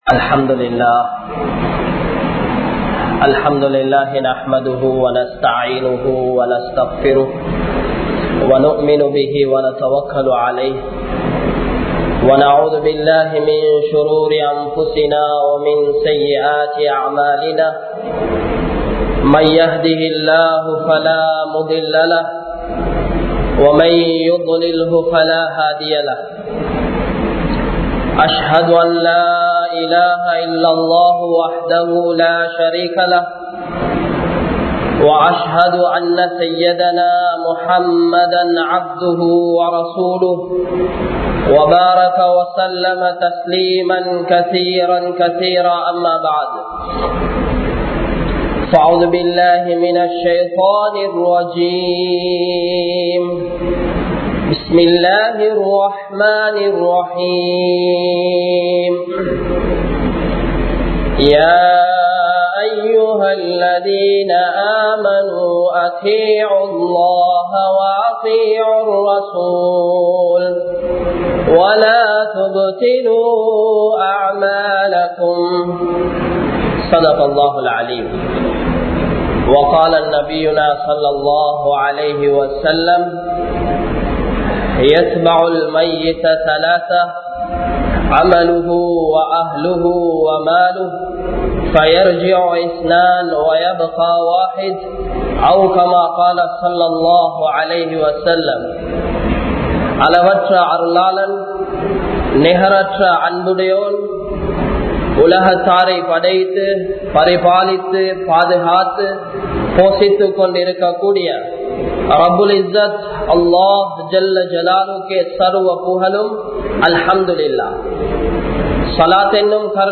Poraamien Vilaivuhal (பொறாமையின் விளைவுகள்) | Audio Bayans | All Ceylon Muslim Youth Community | Addalaichenai